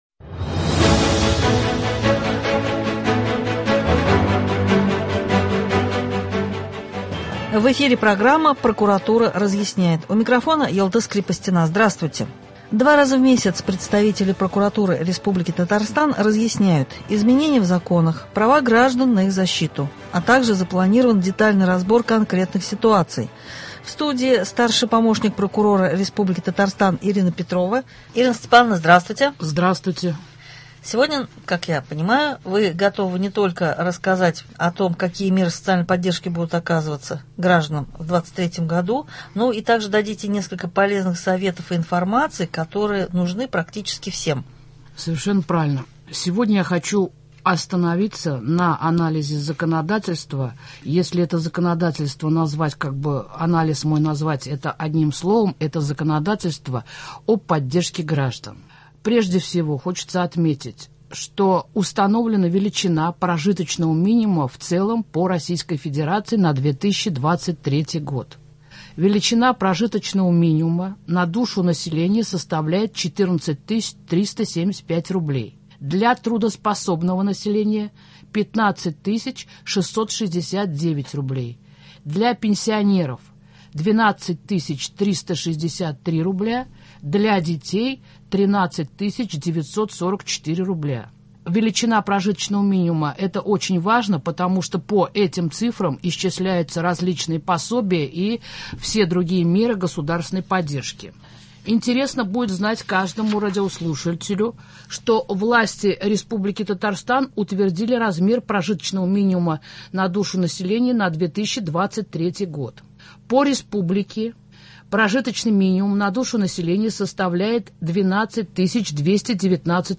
В студии